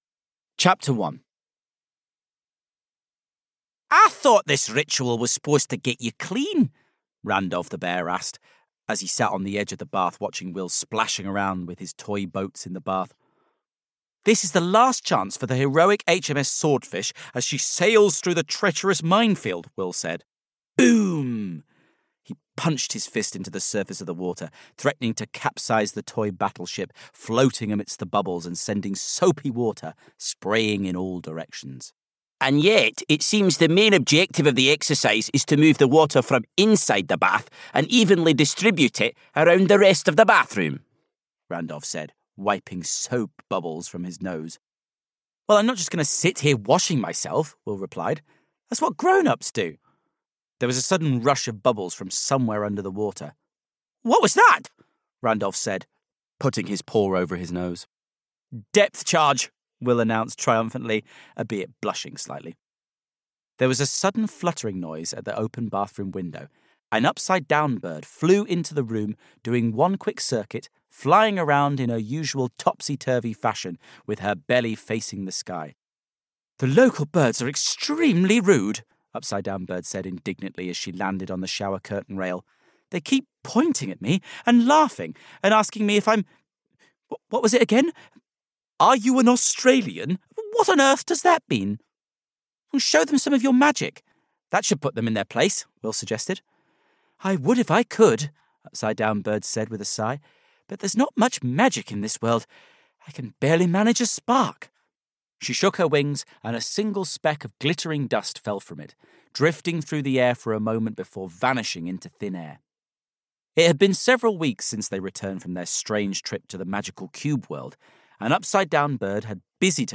Audio knihaThe Awesome Adventures of Will and Randolph: The Killer Kipper (EN)
Ukázka z knihy